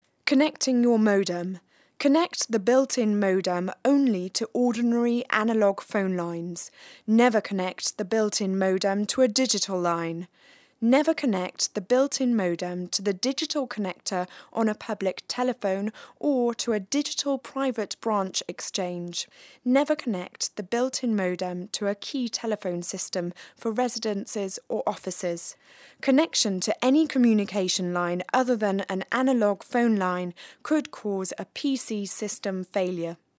dynamisch, frisch, mittelkräftig, sanft, seriös, weich
britisch
Sprechprobe: eLearning (Muttersprache):
dynamic, fresh, soft, serious voice.